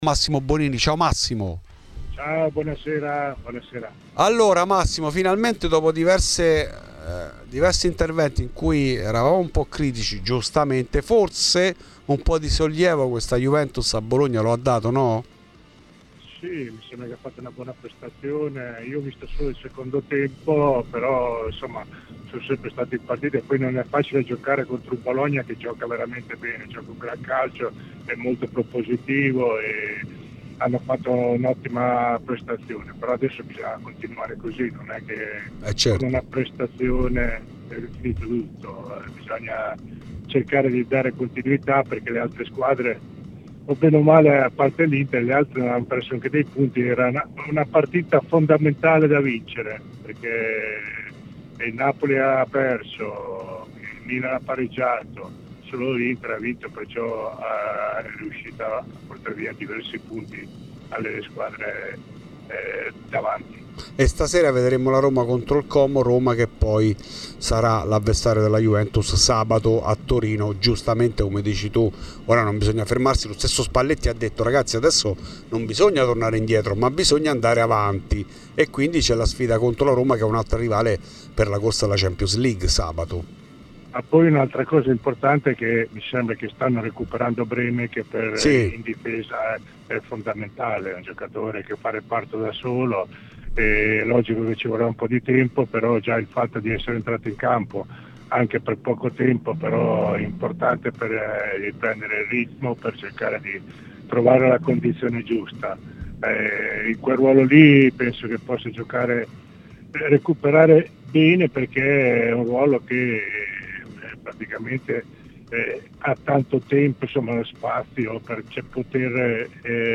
L'ex Juve Massimo Bonini è intervenuto a Radio Bianconera per parlare della prestazione della squadra di Luciano Spalletti a Bologna: "Ha fatto una buona prestazione, sono sempre stati in partita e non è facile giocare con un Bologna così.